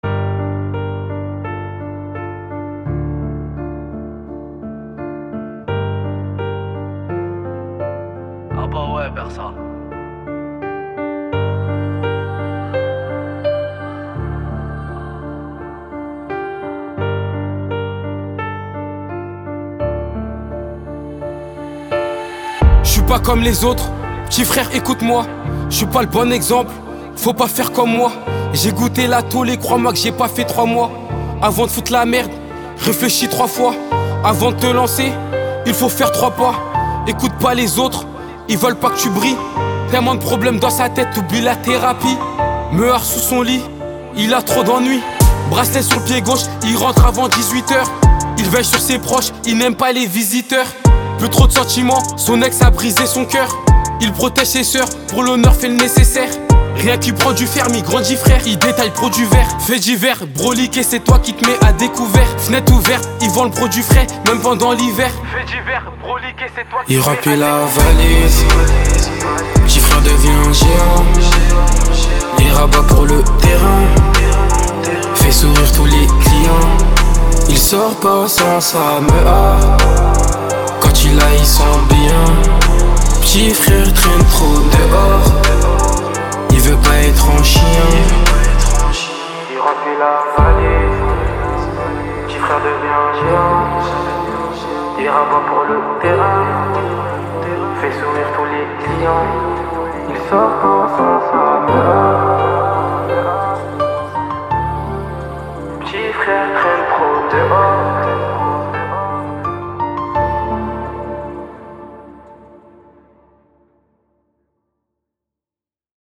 Genres : french rap, french r&b